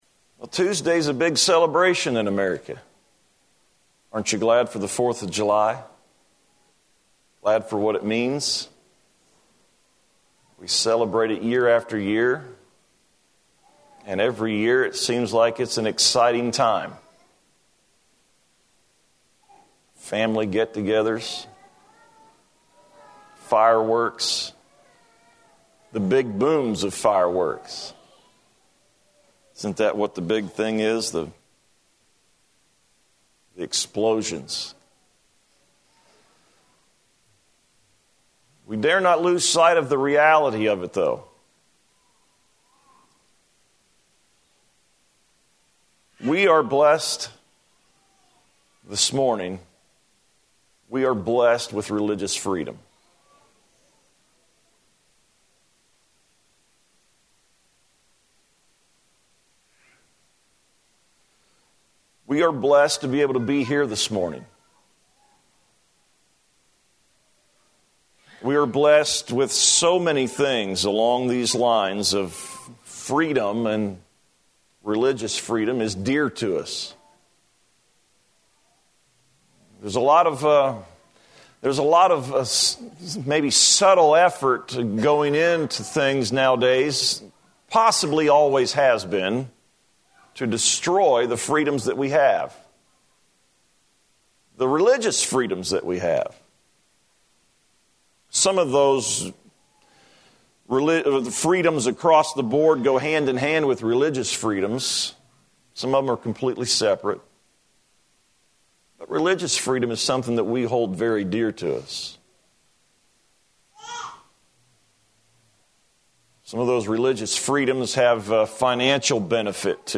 2017-7-2-am-sermon.mp3